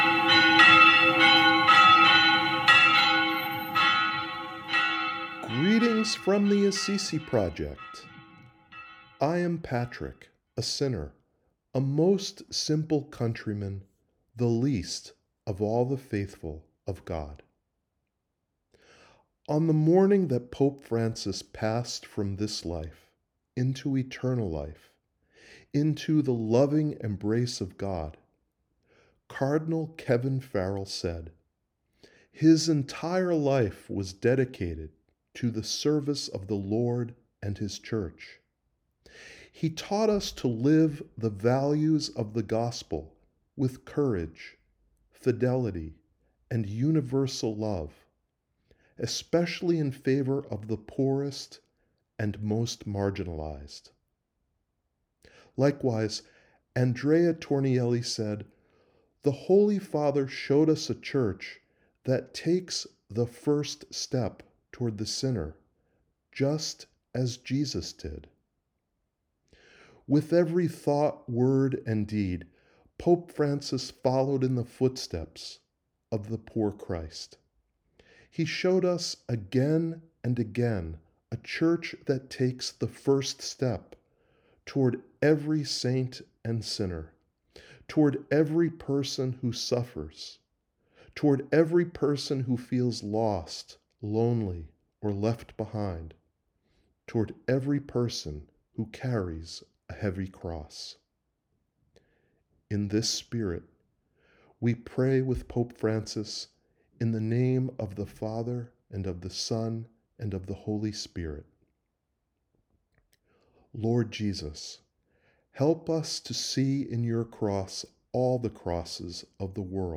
Prayer: Way of the Cross (2019)